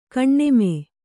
♪ kaṇṇeme